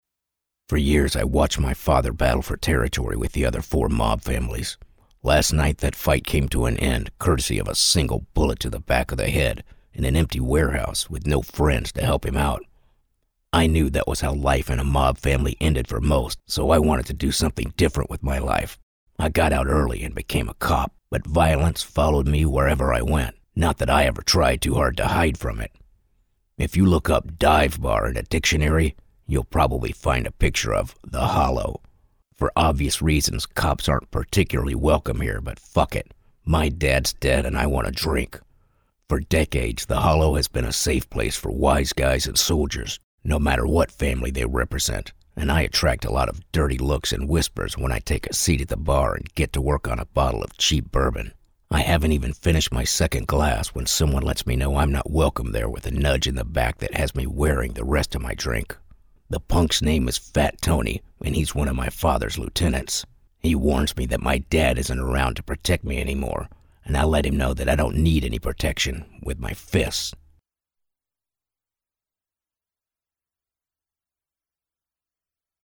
Male
Audiobooks
Audiobook Dark Style